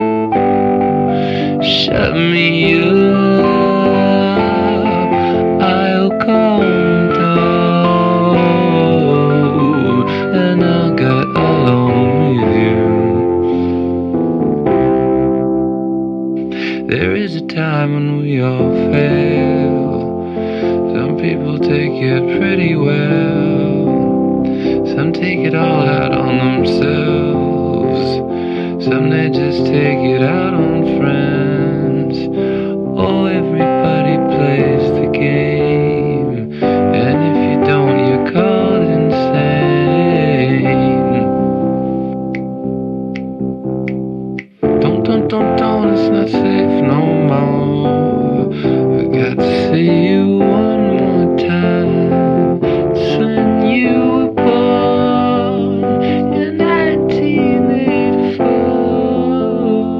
Acoustic Cover